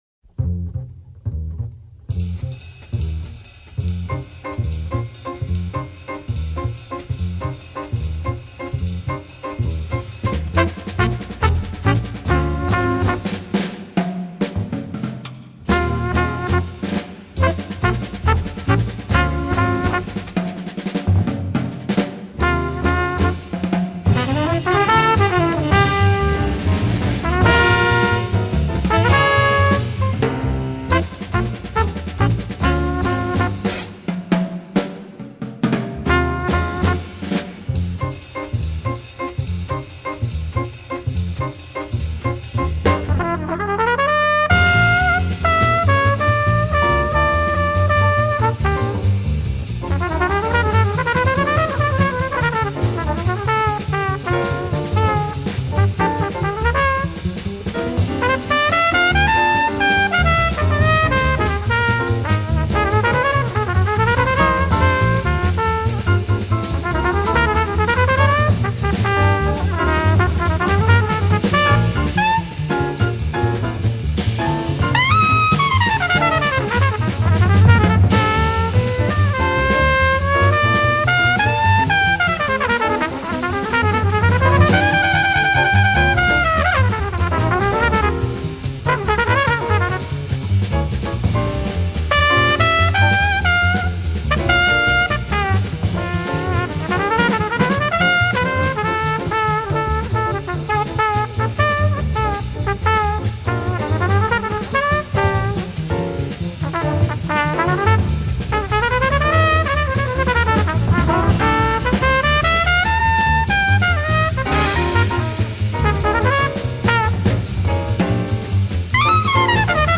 Jazz, Cool Jazz